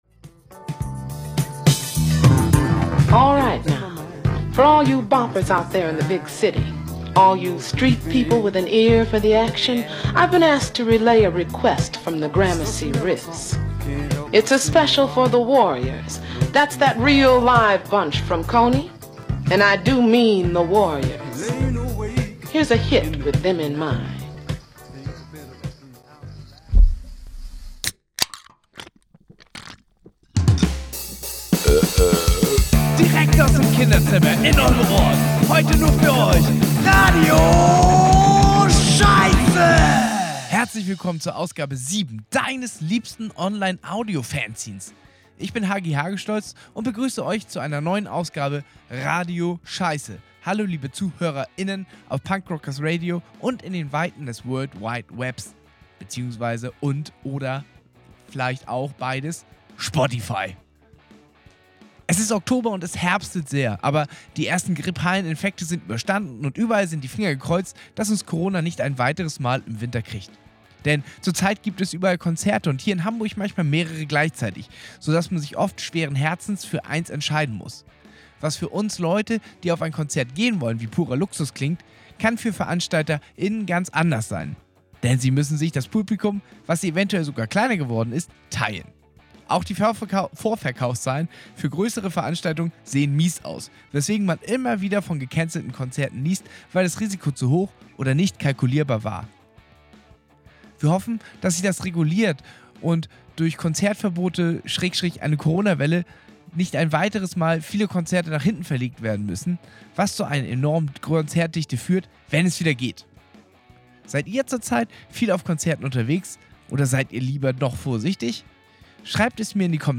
Punkrock Audio-Fanzine